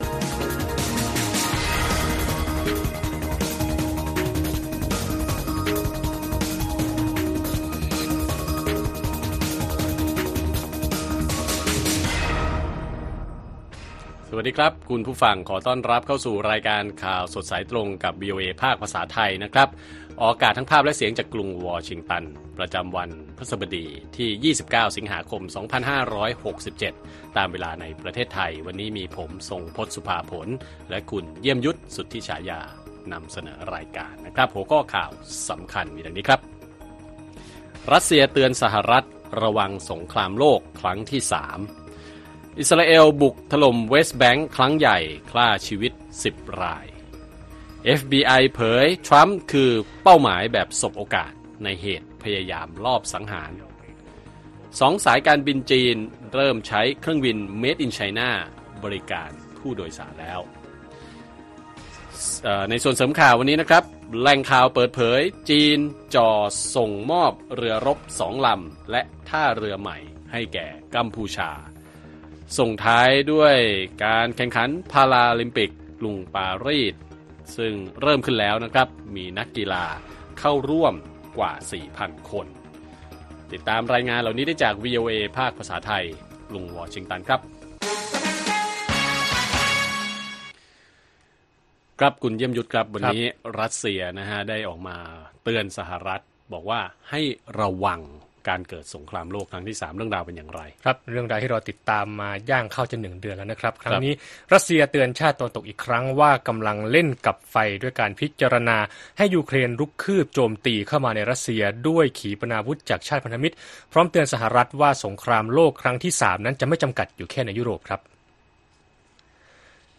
ข่าวสดสายตรงจากวีโอเอไทย วันพฤหัสบดี ที่ 29 ส.ค. 2567